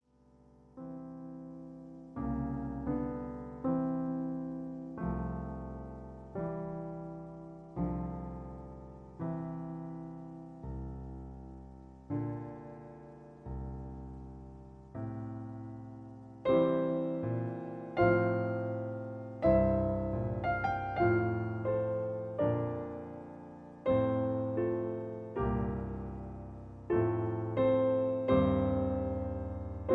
Sonata No. 2 for Violin and Piano in A Major
Piano Accompaniment